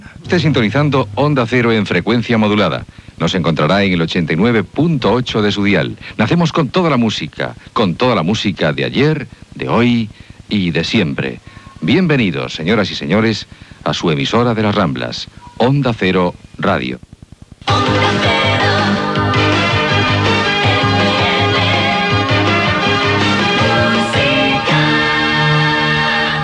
Identificació i indicatiu